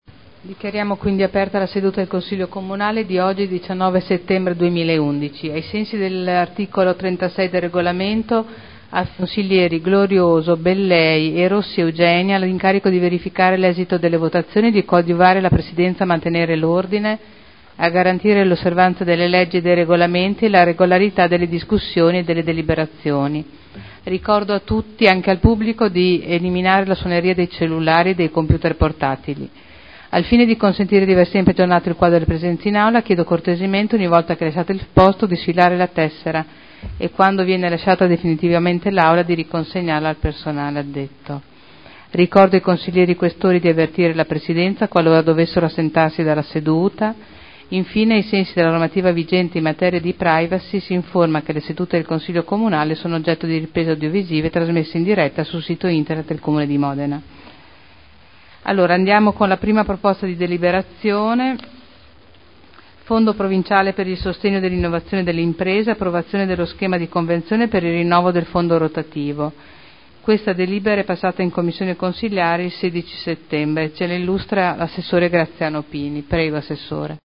Seduta del 19/09/2011. Apertura lavori